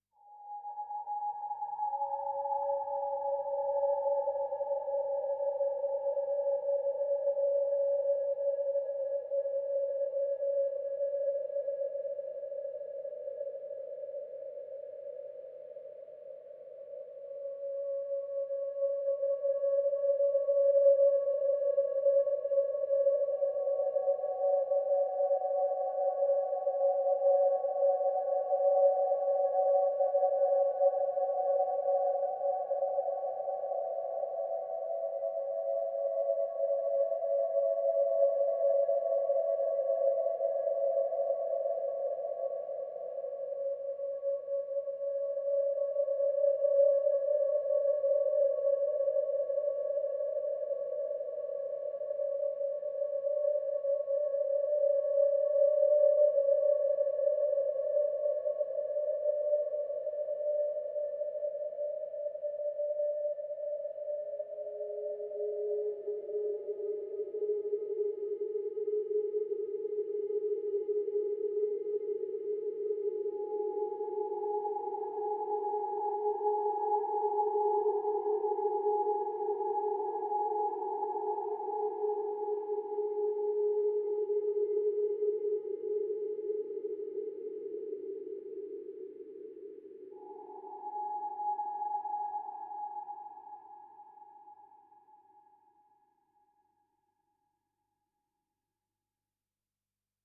Haunting, misty pads create a cold and spooky atmosphere.